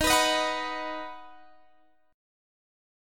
Ebm#5 Chord
Listen to Ebm#5 strummed